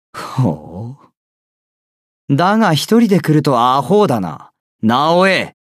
ボイスセレクションと川神通信Ｑ＆Ａです